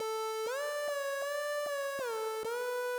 Bestehend aus einer Saw mit gleicher Lautstärke ein Pulse, dazu einen normalen Reverb, Moderat mit wenig Color. Glide habe ich etwa 50%, hört sich ganz gut an.
Den habe ich auch noch Moderat eingebunden, was das ganze noch etwas sanfter und voller macht.